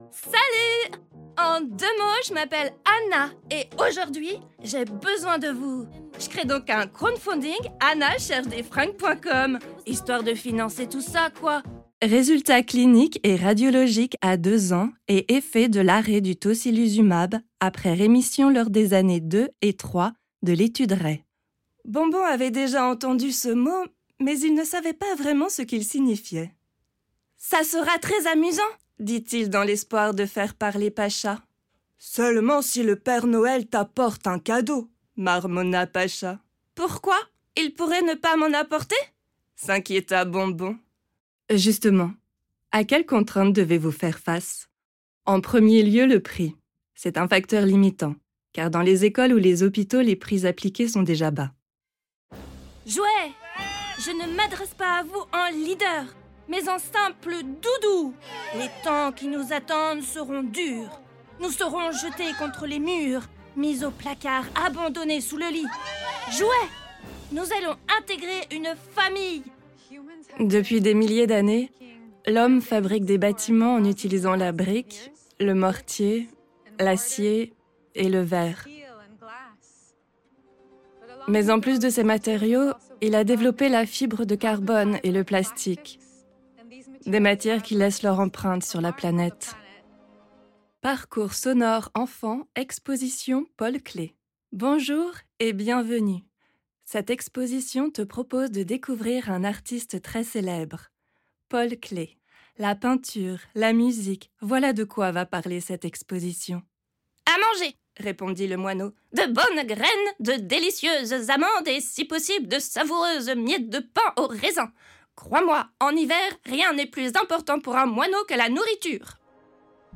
Bande démo vocale (Pub, E-learning, Voice Over, livre audio, audioguide...)
Voix off : timbre médium-aigu, voix douce et apaisante, force de proposition, capacité d'interpréter différents personnages, à insuffler de l'émotion et de capter l'auditeur-rice